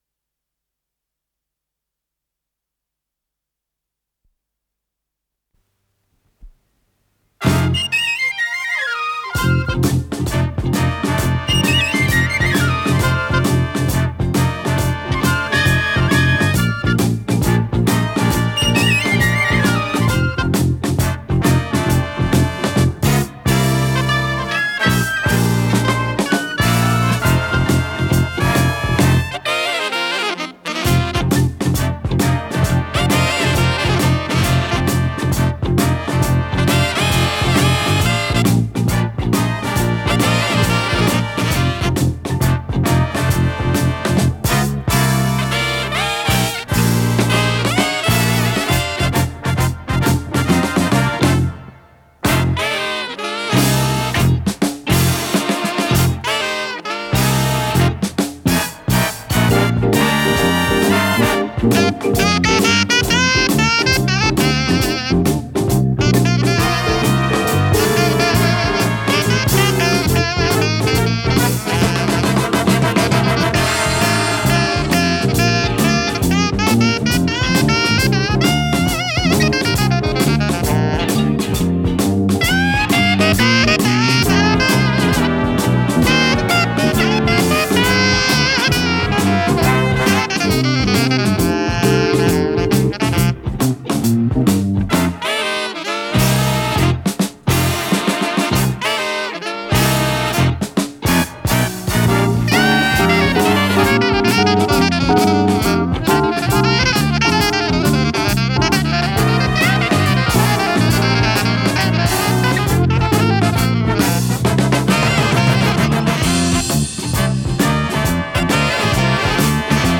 с профессиональной магнитной ленты
альт-саксофон
АккомпаниментИнструментальный ансамбль
ВариантДубль моно